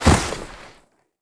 Index of /App/sound/monster/ice_snow_dog
drop_1.wav